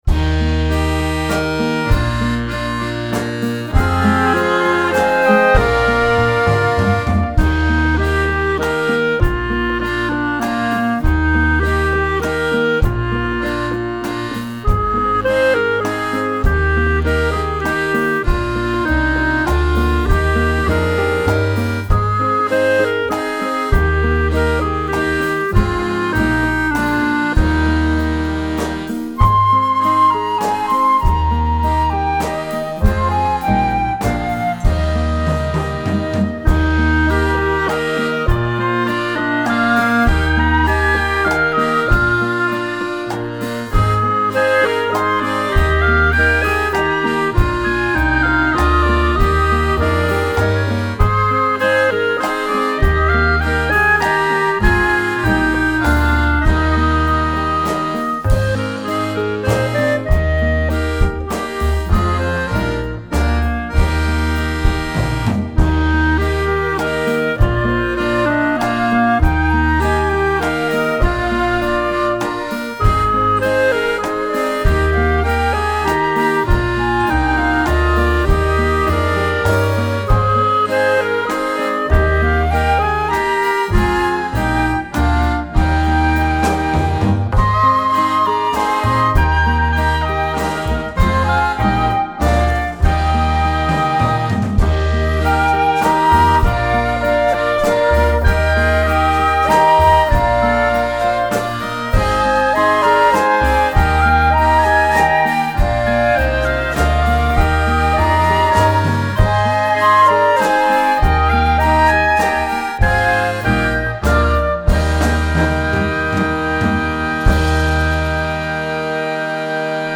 Audio Karaoke
Dat-du-meen-Leevsten-buest_Karaoke.mp3